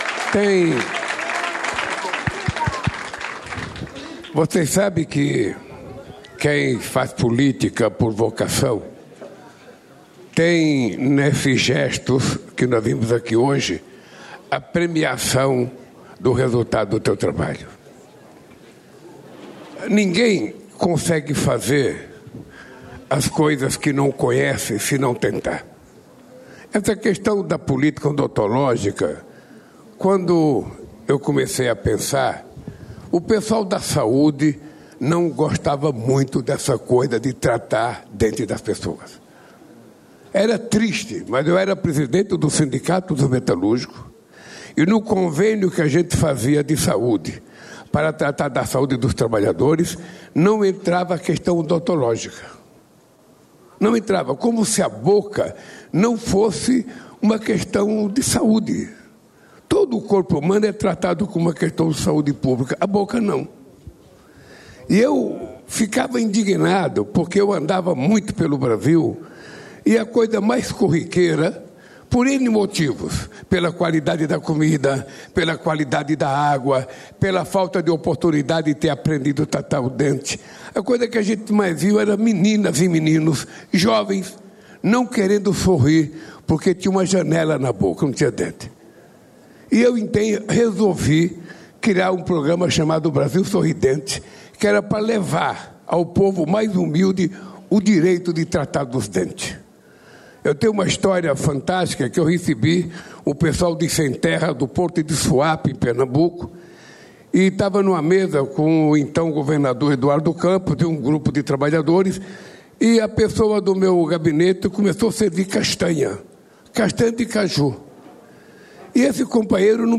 Íntegra do discurso do presidente da República, Luiz Inácio Lula da Silva, na cerimônia de assinatura do contrato de concessão do terminal ITG02, nesta sexta-feira (21), em Itaguaí (RJ).